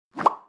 bubble_pop_alt.wav